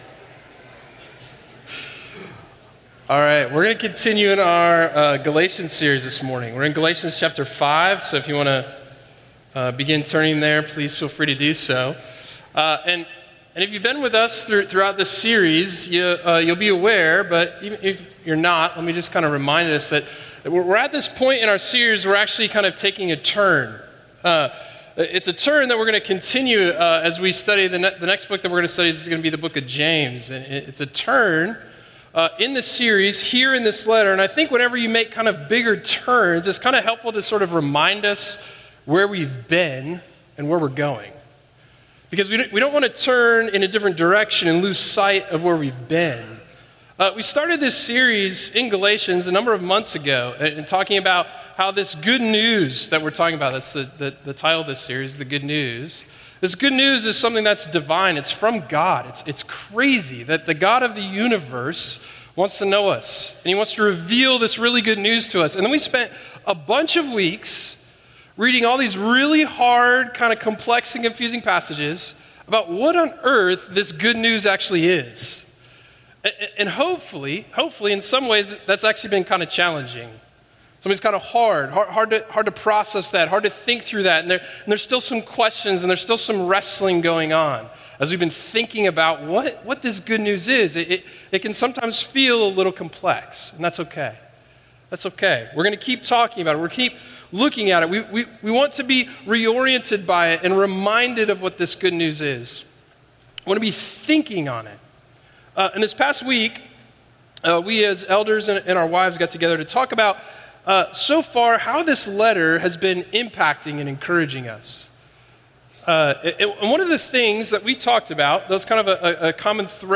A sermon series on Galatians by Crossway Community Church in Charlotte, NC.